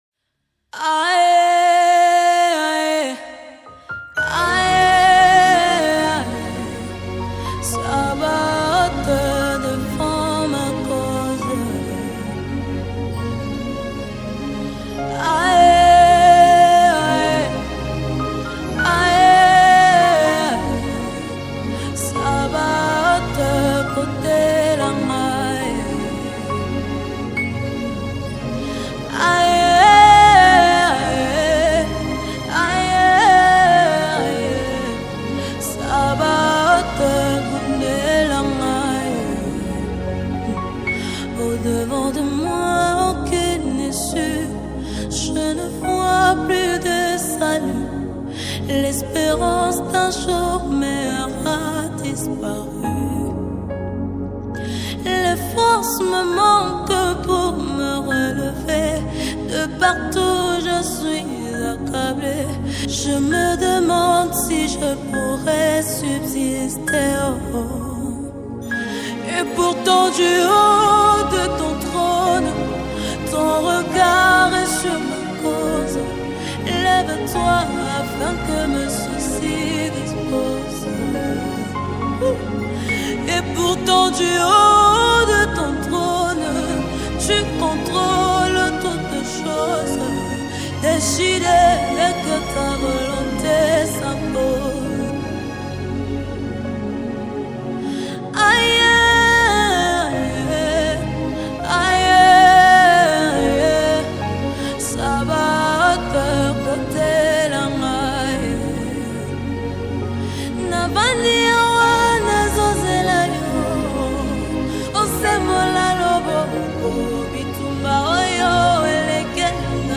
Nigeria Gospel Music